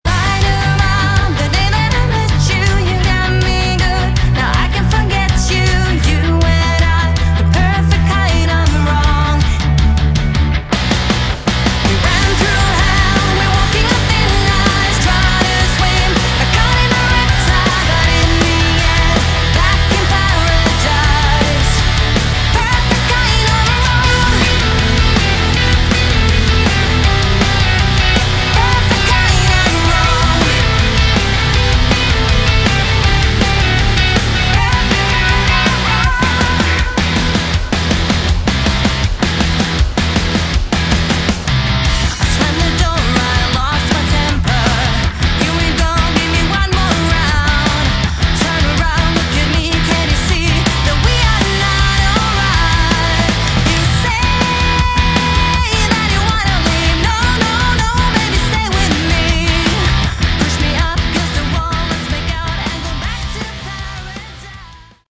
Category: Melodic Rock
vocals
guitar
bass
drums